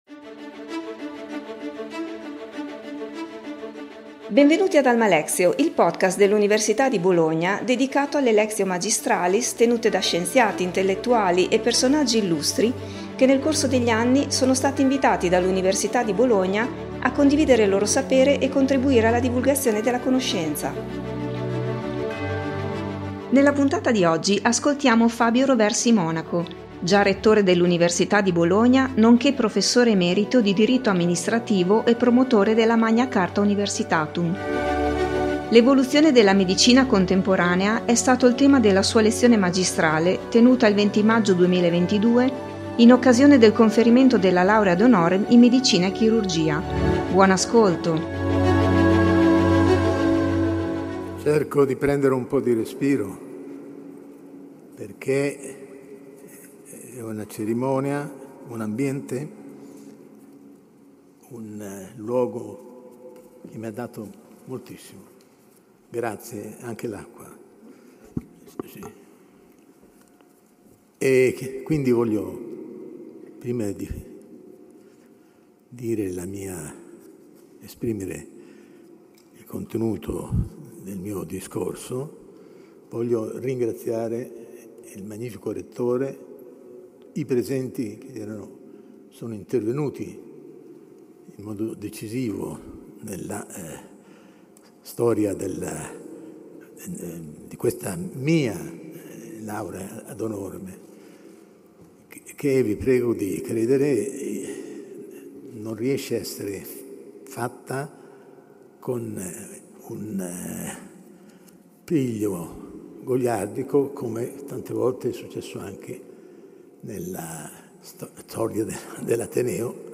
Lectio magistralis sul tema dell’evoluzione della medina contemporanea
in occasione del conferimento della Laurea ad honorem in Medicina e Chirurgia